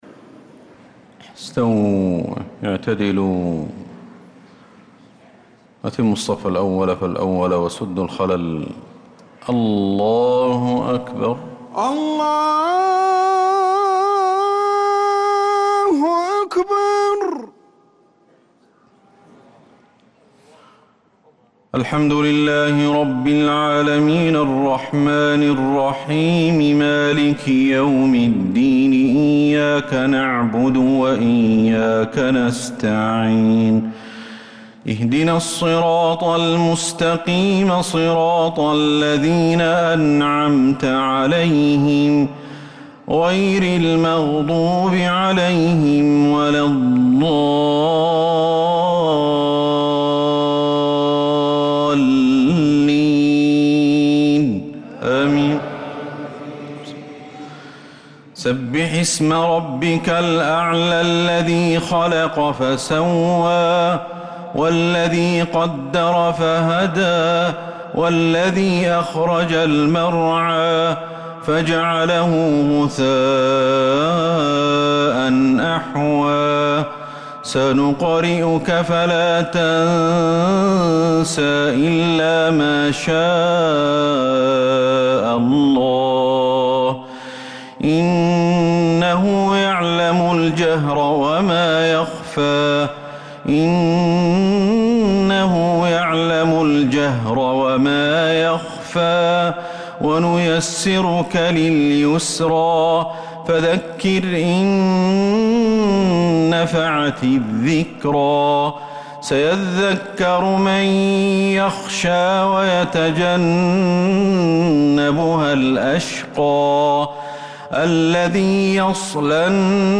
صلاة الجمعة 3-7-1446هـ سورتي الأعلى و الغاشية كاملة | Jumu’ah prayer from Surah Al-a’ala and Al-Ghashiya 3-1-2025 > 1446 🕌 > الفروض - تلاوات الحرمين